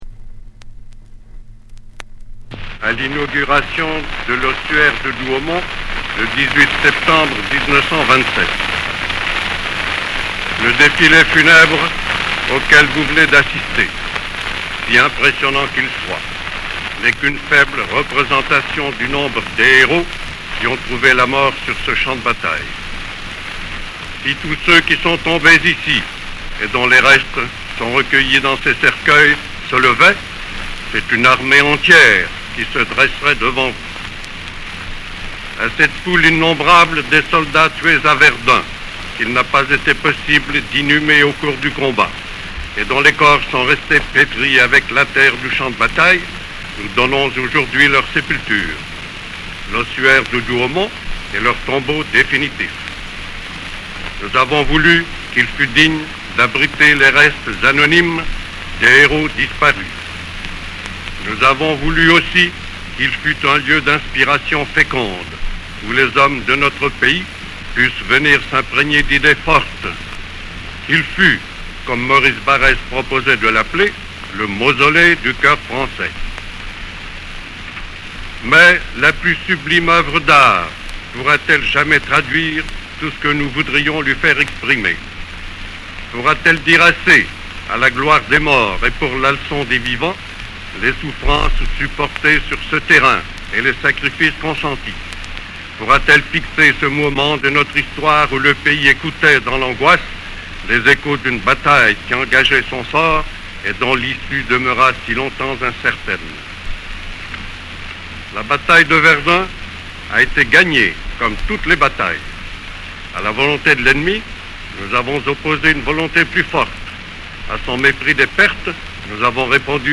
Archives : Allocution du Maréchal Pétain Ossuaire de Douamont 18 septembre 1927
Allocution Marechal Petain Douaumaont 1927.MP3 (16.02 Mo)
Archives sonores de l'allocution prononcée par le Maréchal Pétain à l'occasion de l’inauguration de l'Ossuaire de Douaumont le 18 septembre 1927